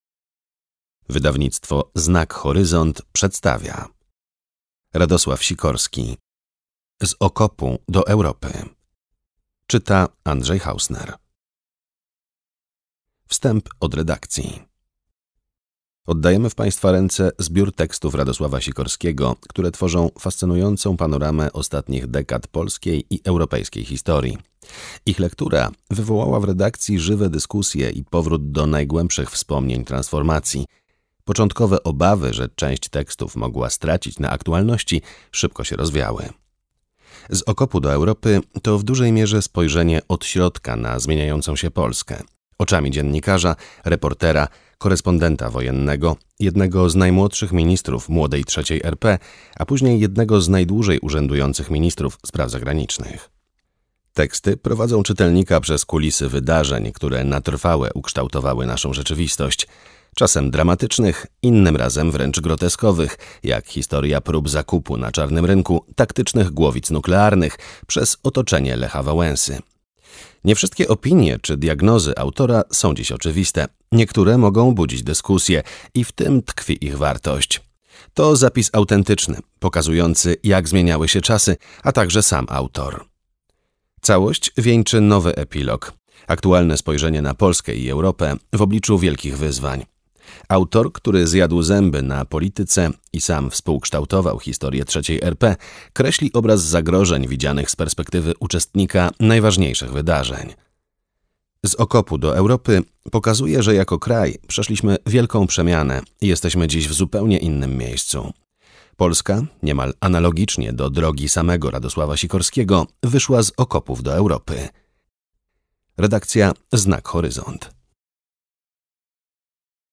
Z okopu do Europy. Trzy dekady w centrum wydarzeń - Sikorski Radosław - audiobook + książka